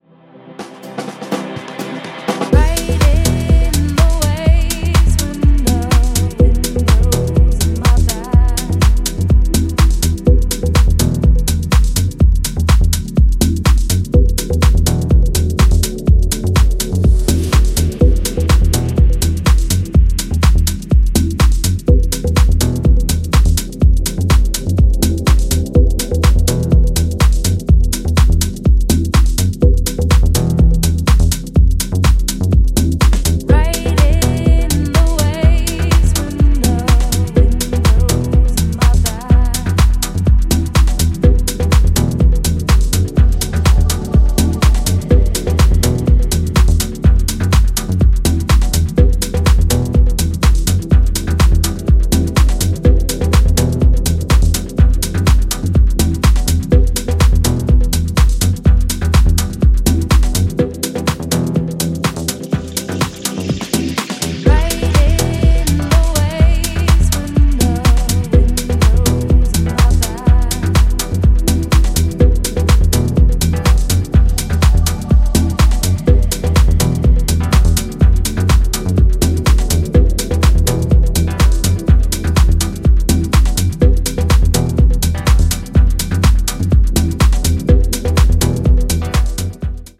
全曲端正に作り込まれたミニマル・テック・ハウスに仕上がっています！